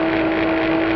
ELEVATOR_Movement_Loop_Mechanic_loop_mono.wav